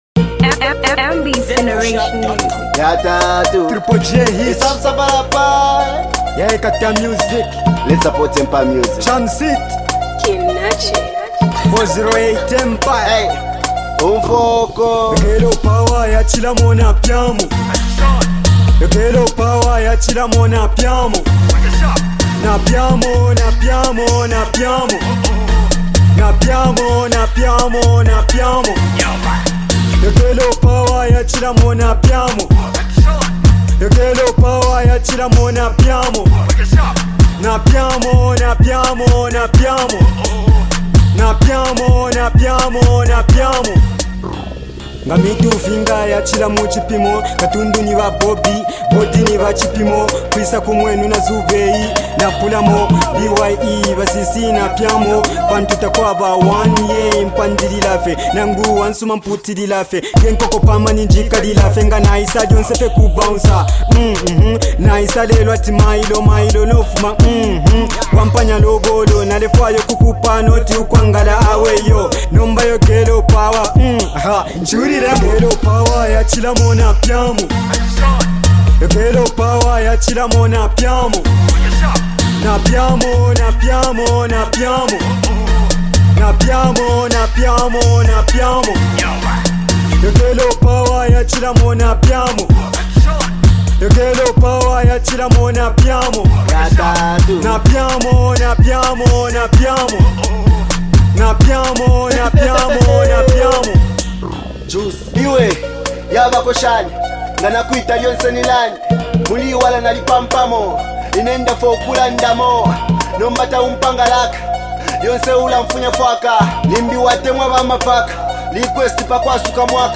Secular-Dancehall